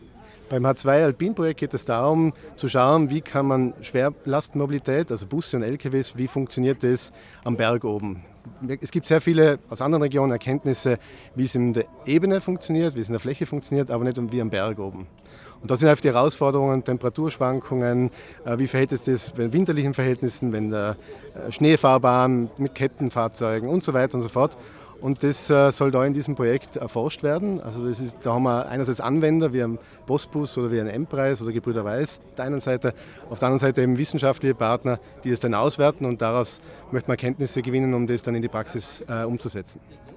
Landeshauptmann Stellvertreter Josef Geisler spricht über die nächsten Schritte im Hinblick auf die Energiewende 2050 und beschreibt die Bedeutung Tirols als alpiner Standort.